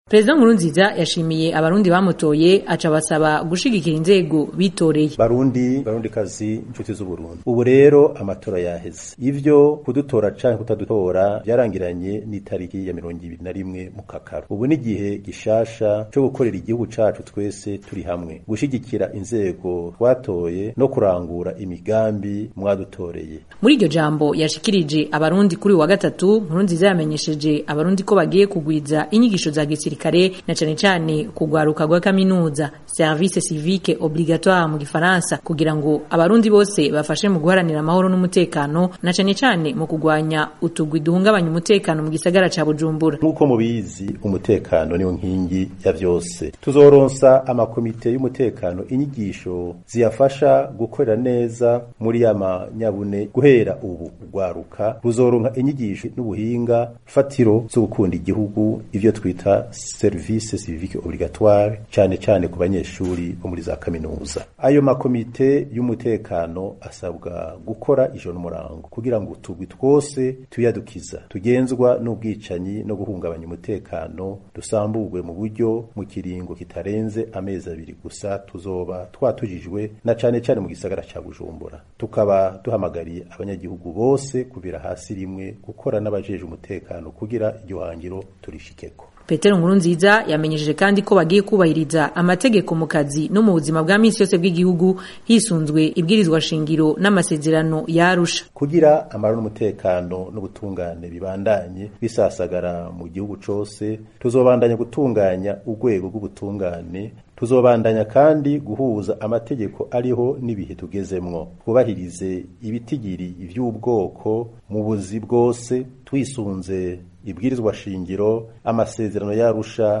Ijambo rya Prezida Nkurunziza